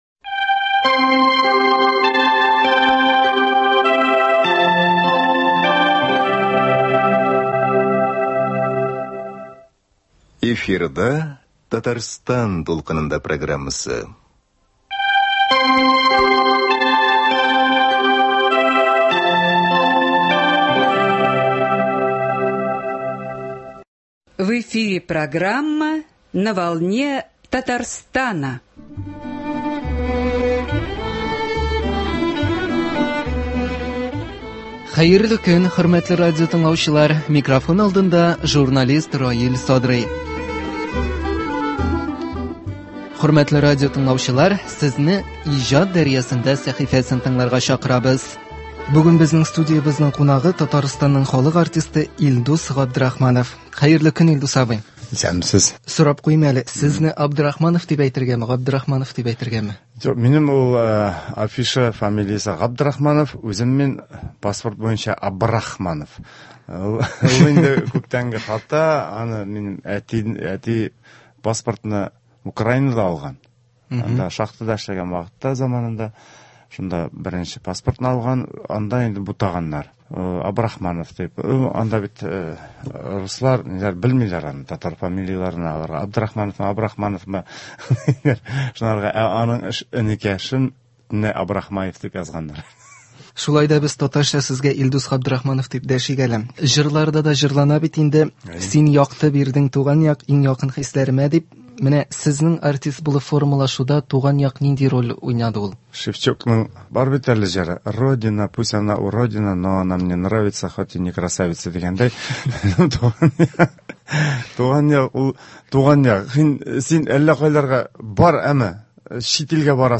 Студиябезнең бүгенге кунагы